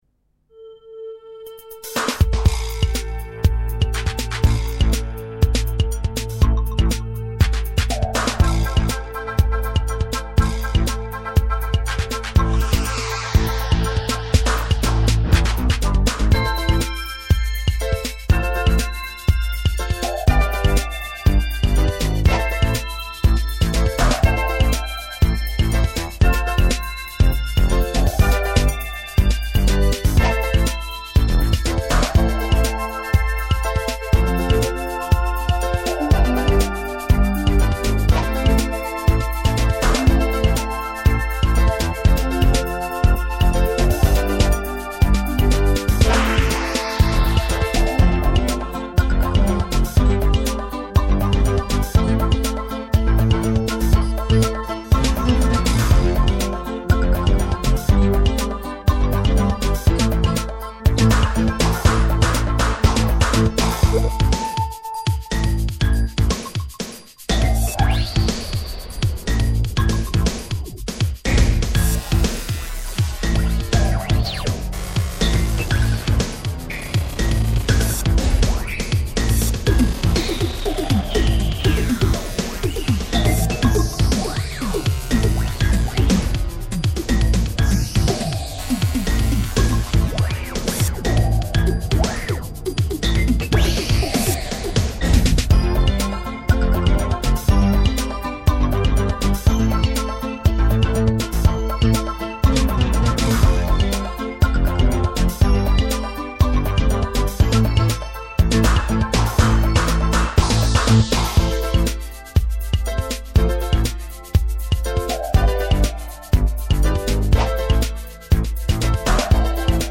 Real Techno-Pop.